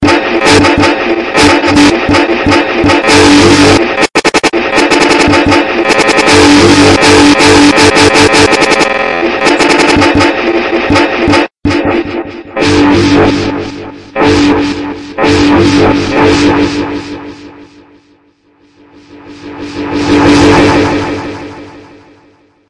吉他简介
描述：用电吉他和鼓组制作的吉他介绍。
标签： 和弦 节奏 扭曲 吉他
声道立体声